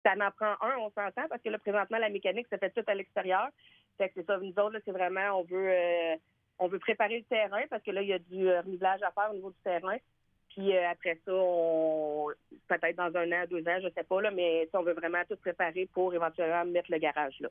La mairesse de Montcerf-Lytton, Véronique Danis, mentionne que le terrain en question devrait à terme accueillir un garage.